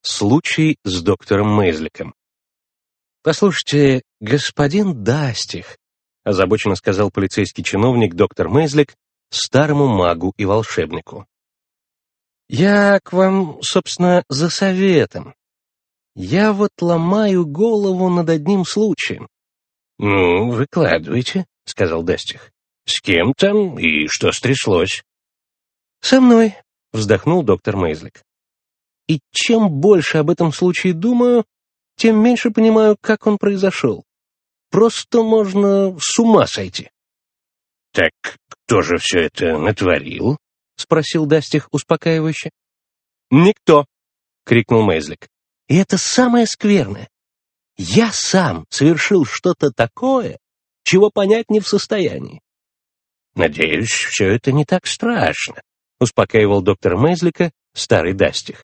Аудиокнига Рассказы из карманов | Библиотека аудиокниг